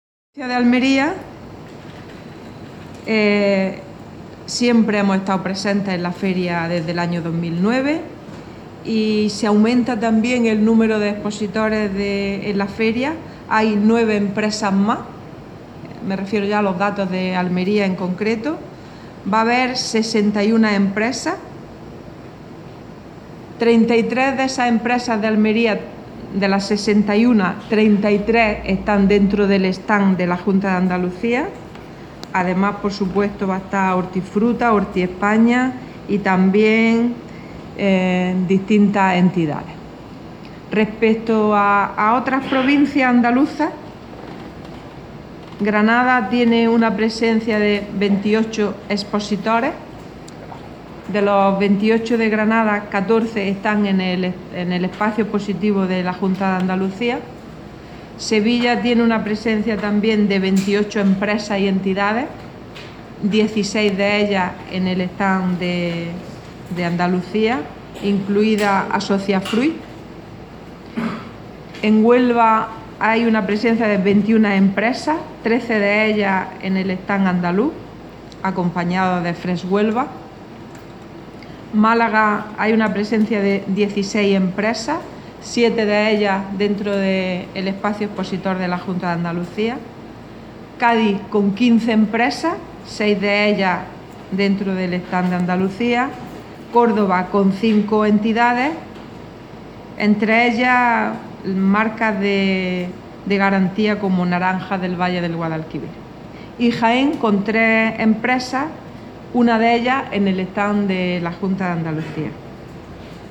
Declaraciones Carmen Ortiz sobre presencia andaluza